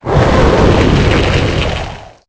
Fichier:Cri 0839 EB.ogg — Poképédia
Cri_0839_EB.ogg